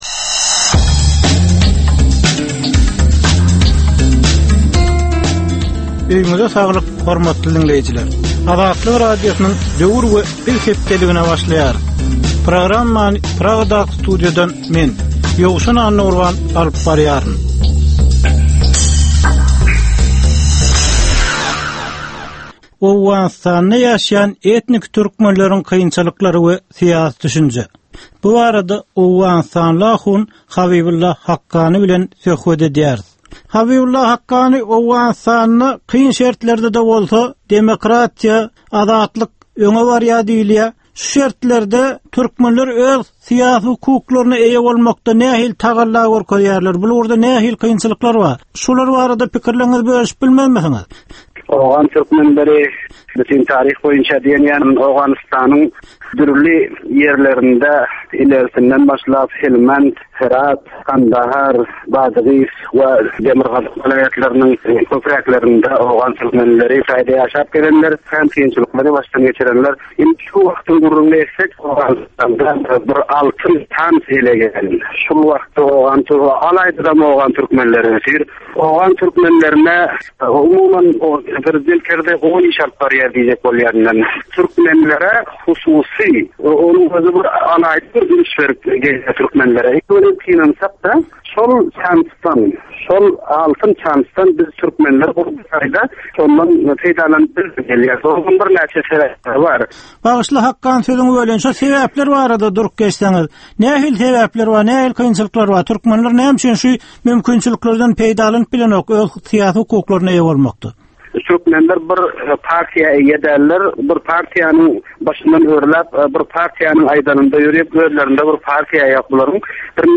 Türkmen jemgyýetindäki döwrüň meseleleri we döwrüň anyk bir meselesi barada ýörite gepleşik. Bu gepleşikde diňleýjiler, synçylar we bilermenler döwrüň anyk bir meselesi barada pikir öwürýärler, öz garaýyşlaryny we tekliplerini orta atýarlar.